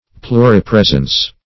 Search Result for " pluripresence" : The Collaborative International Dictionary of English v.0.48: Pluripresence \Plu`ri*pres"ence\, n. [Pluri- + presence.] Presence in more places than one.